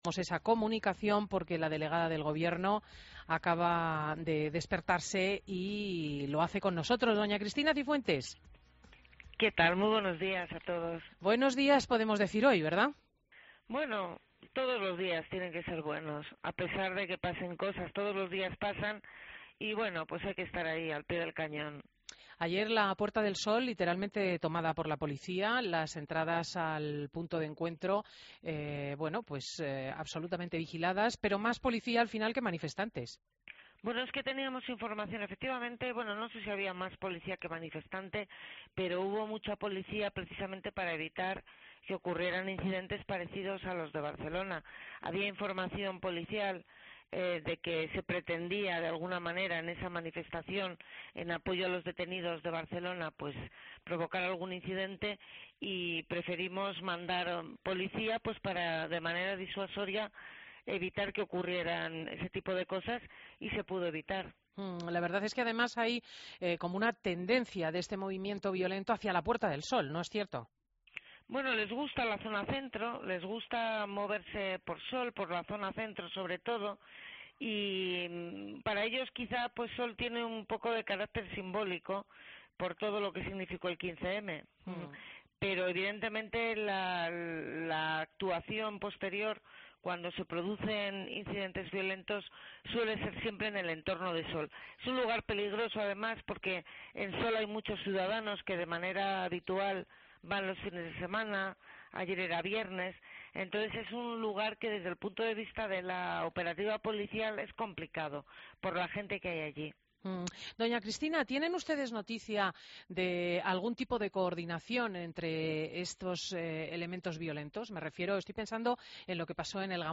Entrevista a Cristina Cifuentes en Fin de Semana